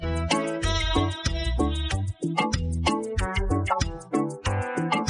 reggae_fusion.mp3